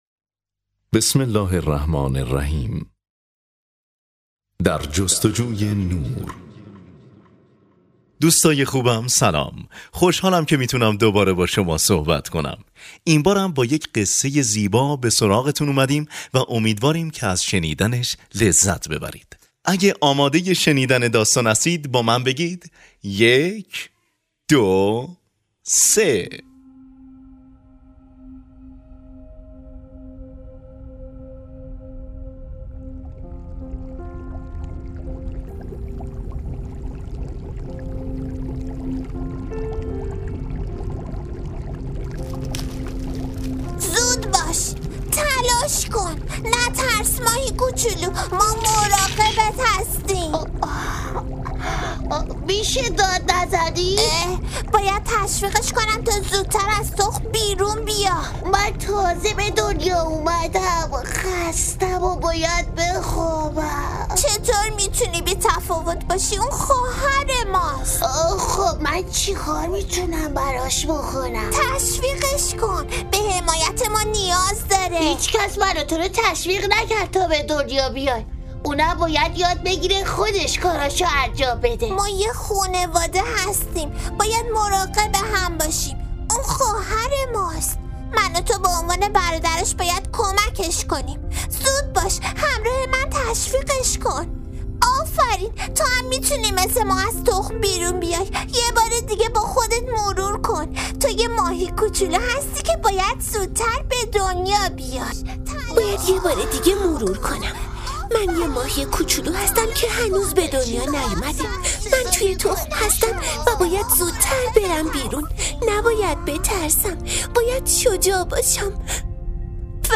نمایشنامه صوتی در جستجوی نور - قسمت اول - متفرقه با ترافیک رایگان